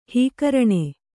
♪ hīkarṇe